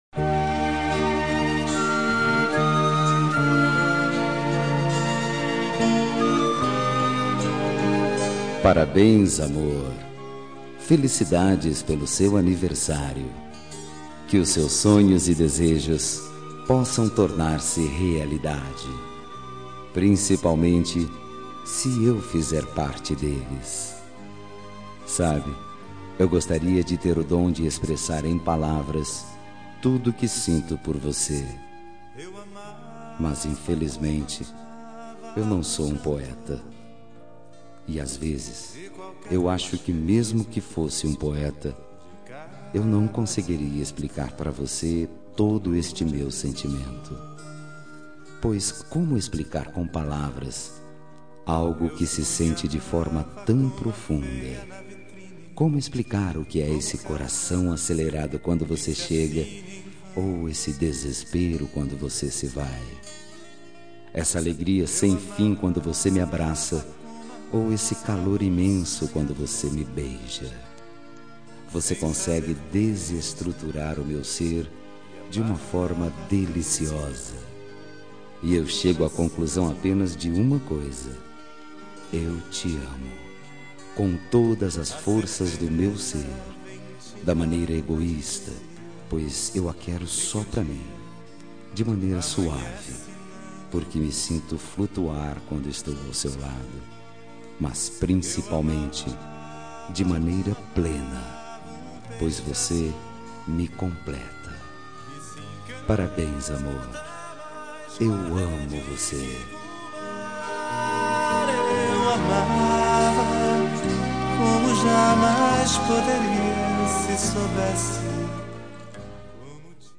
Telemensagem de Aniversário Romântico – Voz Masculino – Cód: 1060